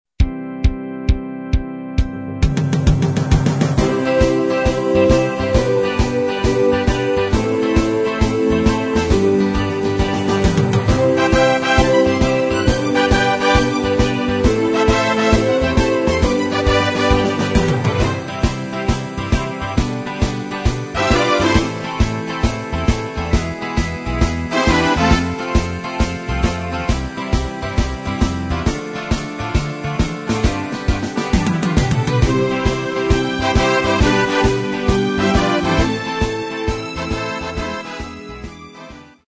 • Music Genre: Pop